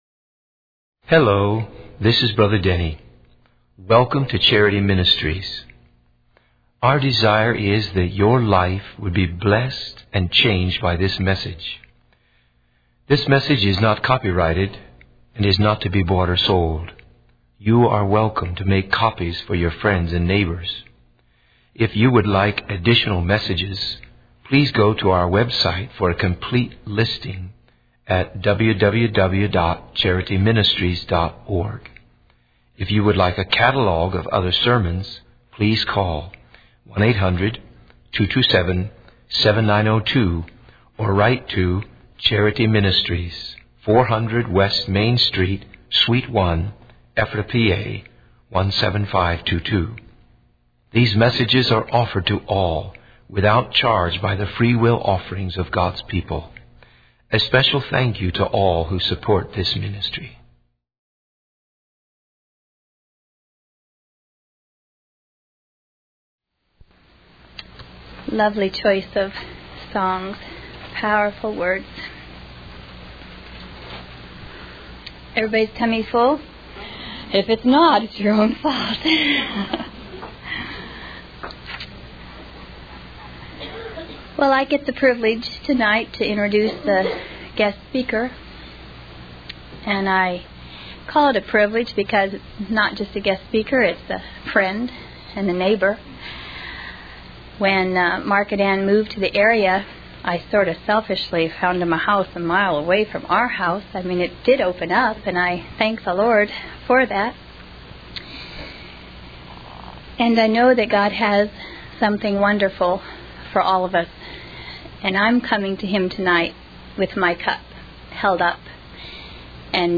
In this sermon, the speaker emphasizes the importance of finding purpose and hope in life. They encourage listeners to break the cycle of broken homes and pain, and to set a vision for their children's future. The speaker also highlights the role of mothers in shaping their children's success or failure.